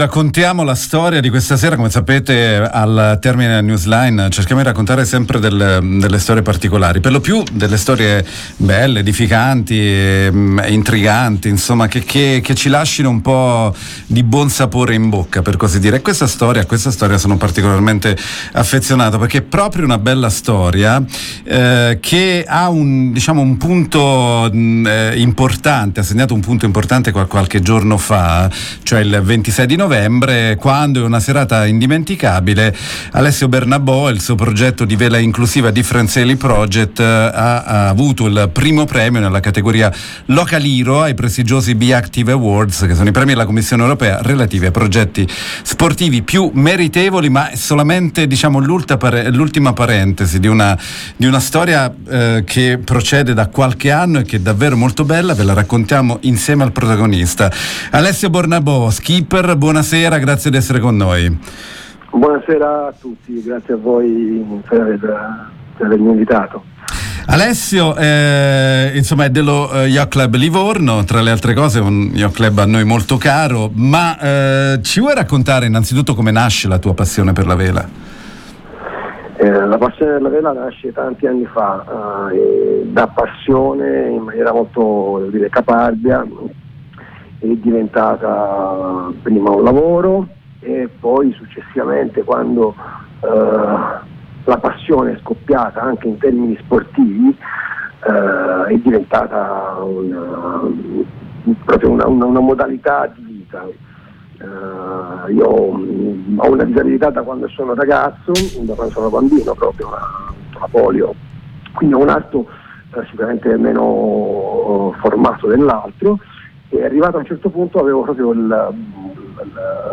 Un meritato riconoscimento per un un progetto che unisce innovazione, passione per il mare, sostenibilità e inclusione. Lo abbiamo intervistato